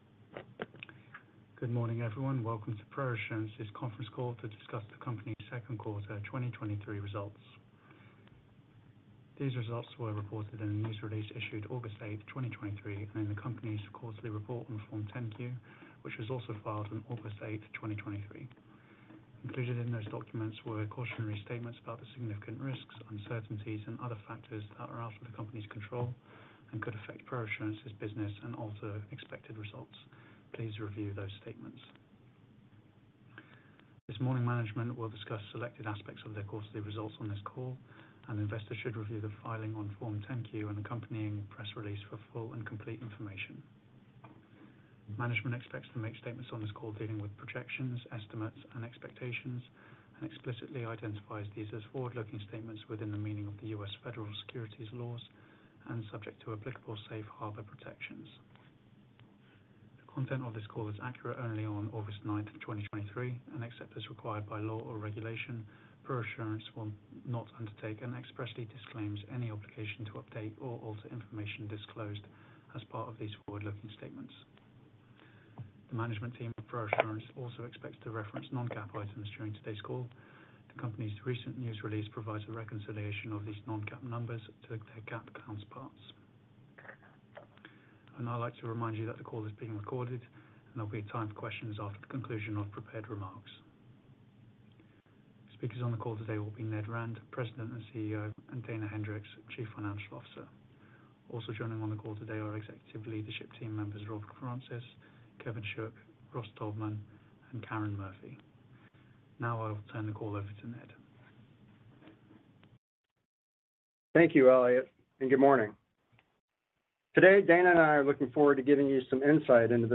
Q2 2023 Earnings Conference Call | ProAssurance Corporation